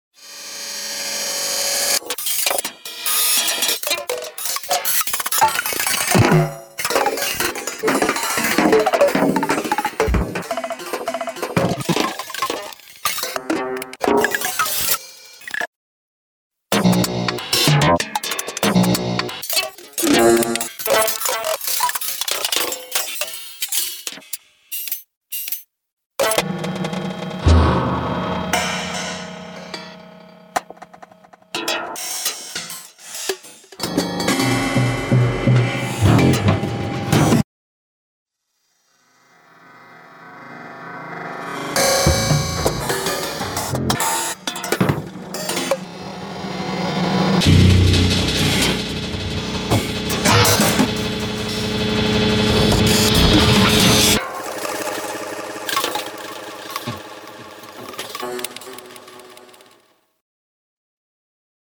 Structured-random miniatures.